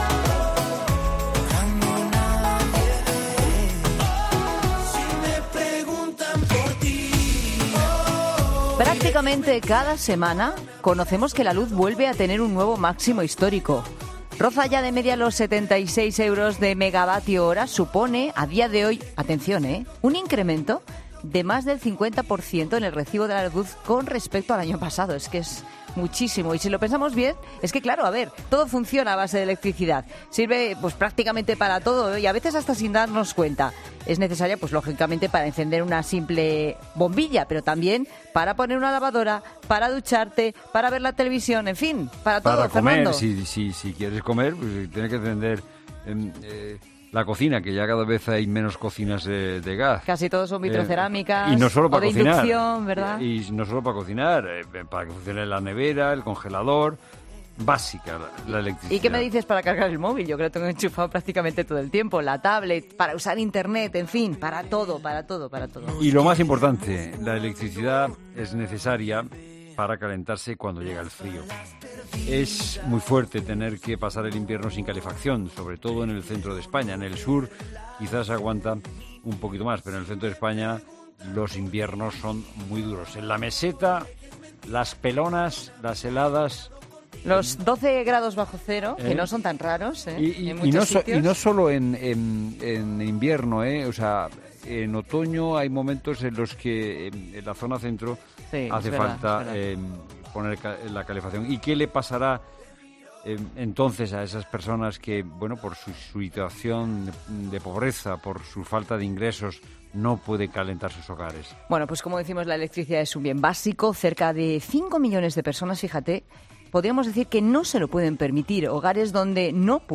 ESCUCHA LA ENTREVISTA COMPLETA EN 'LA TARDE' La electricidad es un bien básico y cerca de 5 millones de personas no se la pueden permitir.